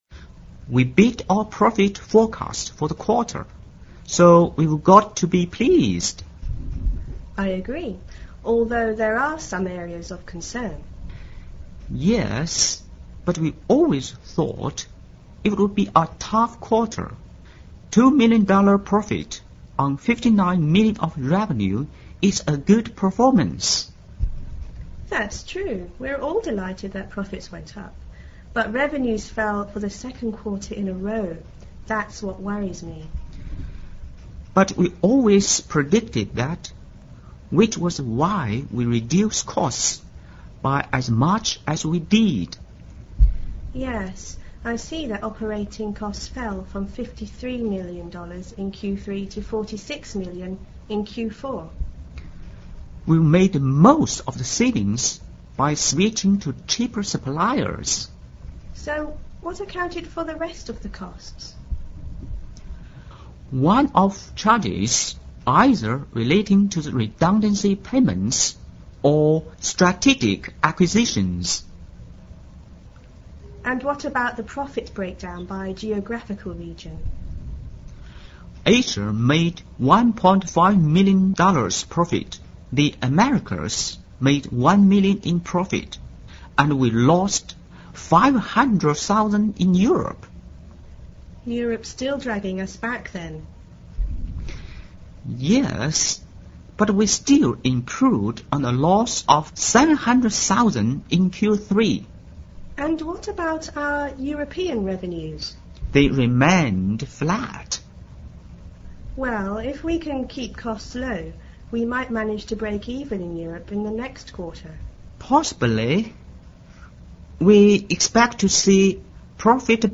Listen to the dialogue between the Company president and chaimp3an,